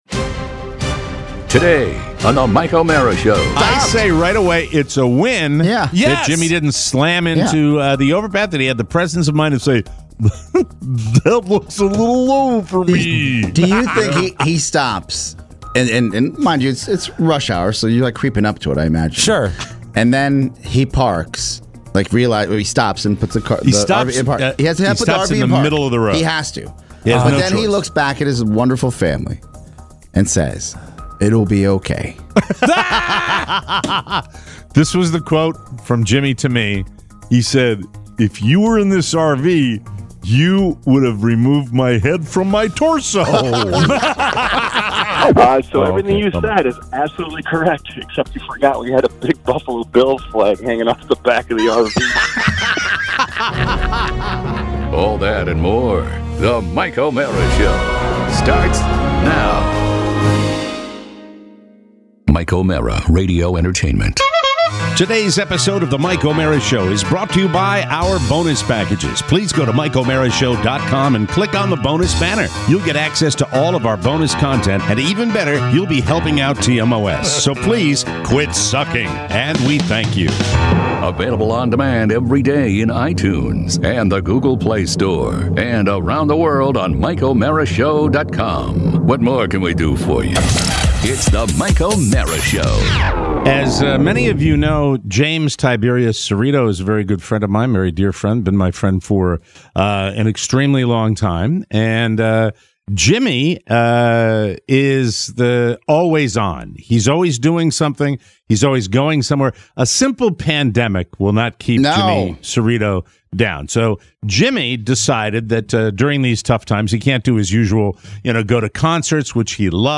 We're back live in studio